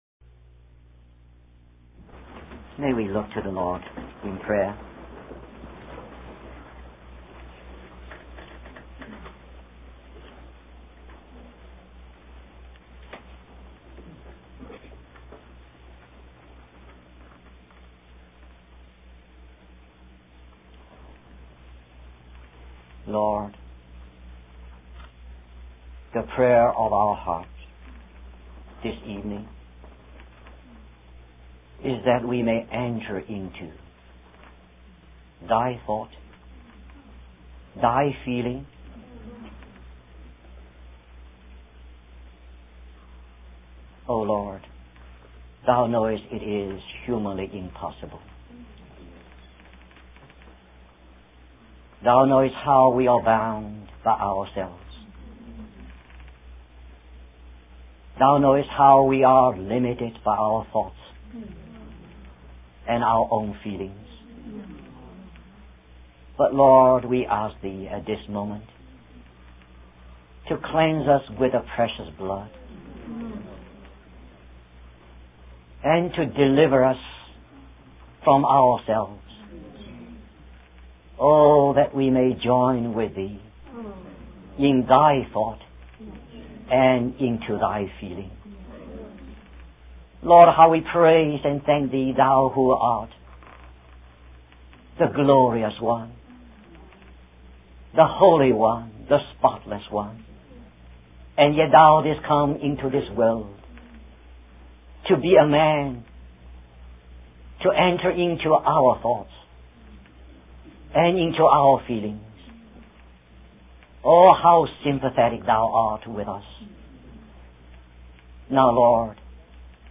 In this sermon, the speaker emphasizes the importance of having a vision of the Lord and the Church as God intends it to be. He refers to the seven golden landscapes representing the seven churches in Asia, which were meant to bear the testimony of Jesus.